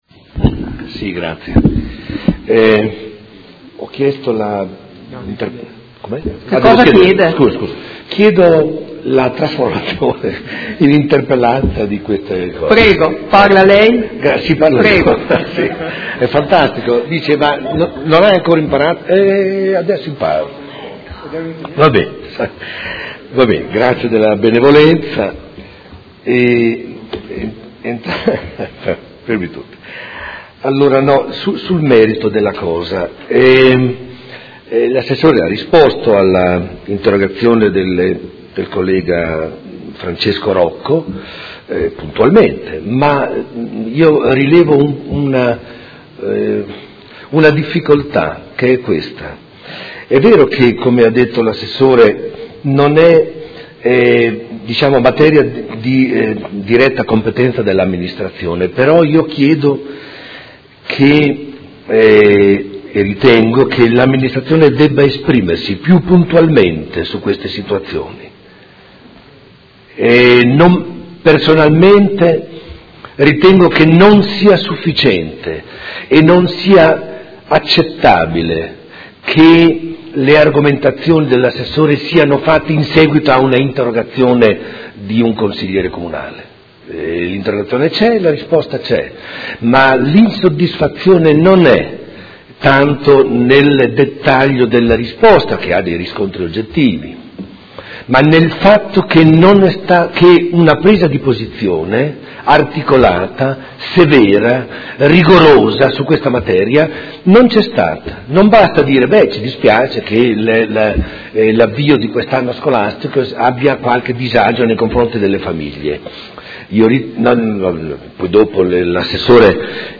Seduta del 20/10/2016. Chiede trasformazione in interpellanza e dibattito su interrogazione del Consigliere Rocco (FaS-SI) avente per oggetto: Inizio anno scolastico con gravi difficoltà organizzative